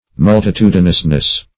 Mul`ti*tu"di*nous*ness, n.